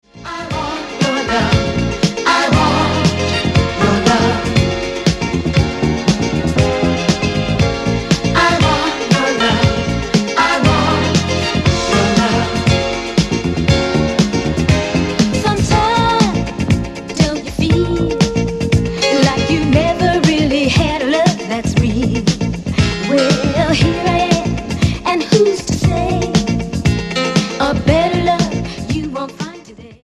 Subtle mixes of classic tracks.